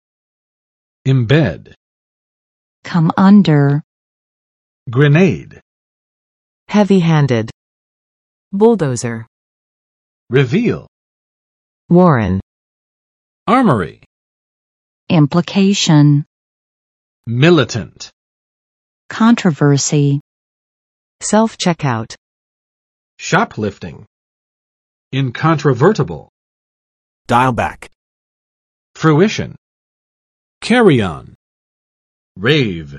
[ɪmˋbɛd] v. to place a journalist in a military unit; 融入